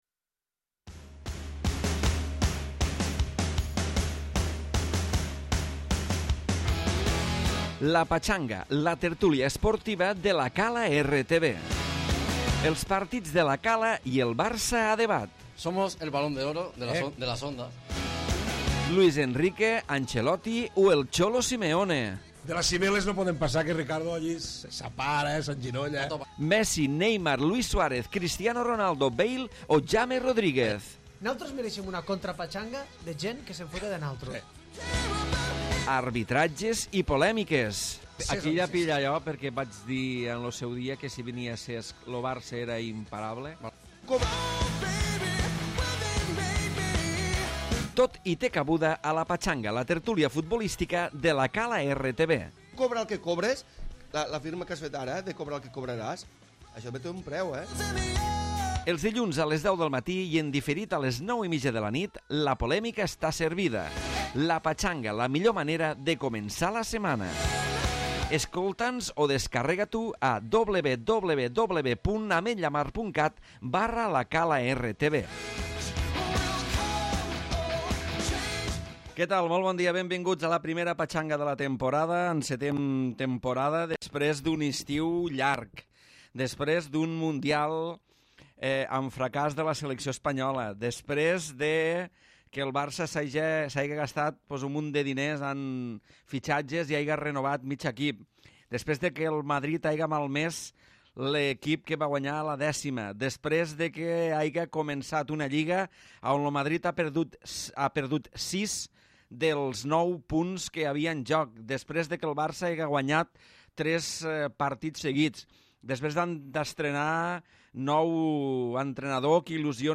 Tertúlia futbolistica